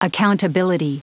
Prononciation du mot accountability en anglais (fichier audio)
Prononciation du mot : accountability